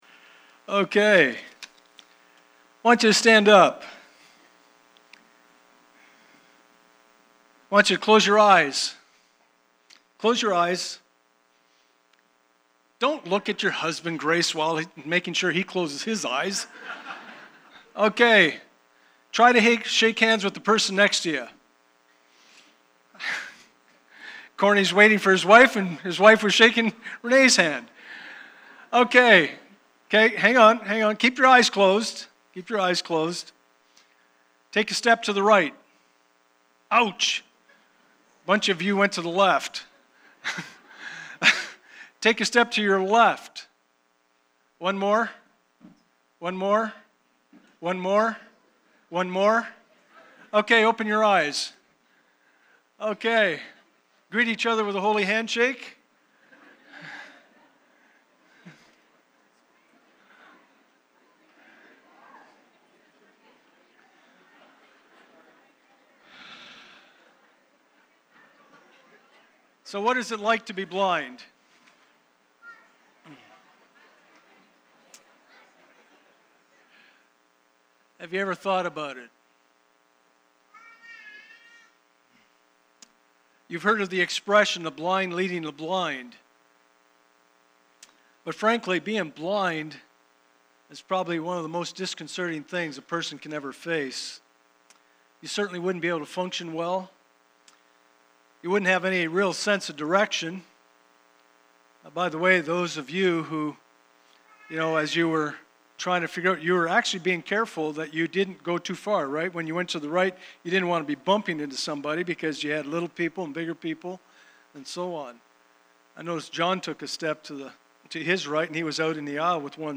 Matthew 9:27-31 Service Type: Sunday Morning « 1 Thessalonians